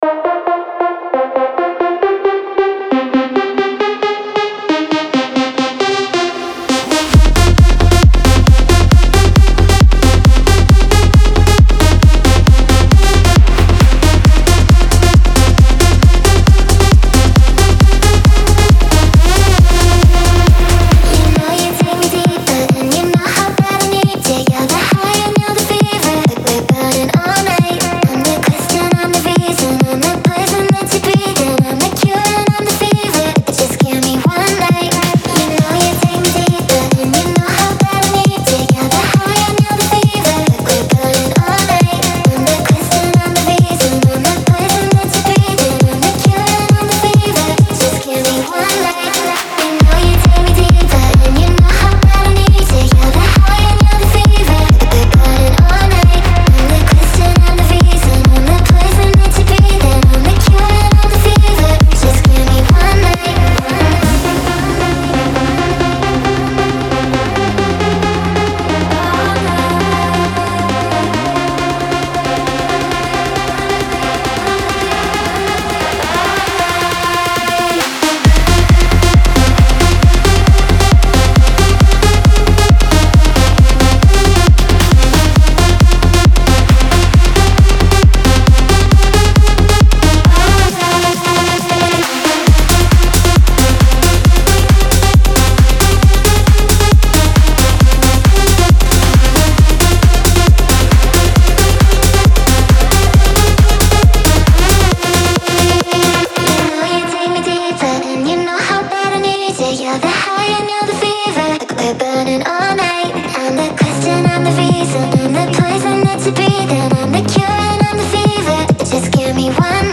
• Жанр: Electronic, Dance, EDM